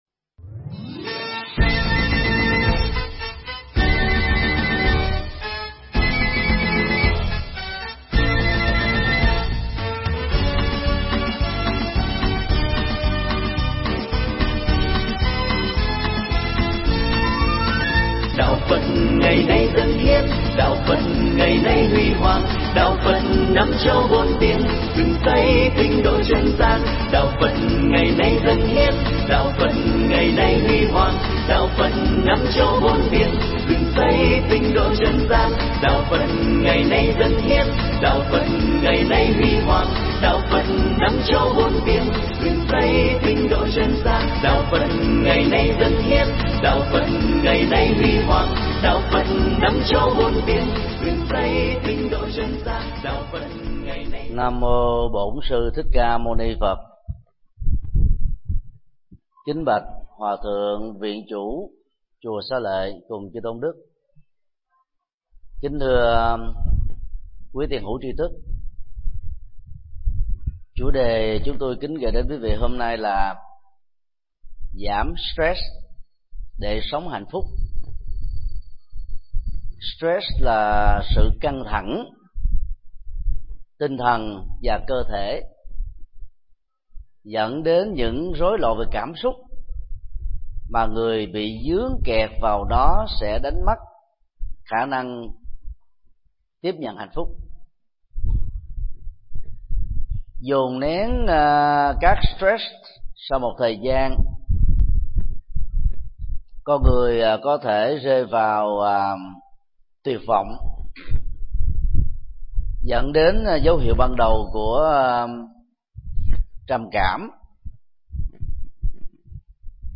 Tải mp3 Pháp thoại Bỏ stress để sống hạnh phúc hơn do thầy Thích Nhật Từ giảng tại chùa Xá Lợi, ngày 20 tháng 05 năm 2012.